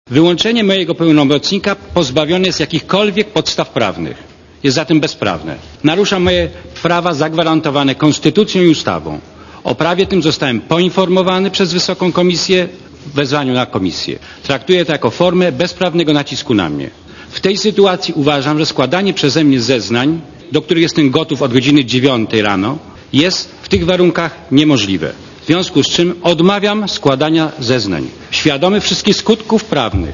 Posłuchaj komentarza Jana Kulczyka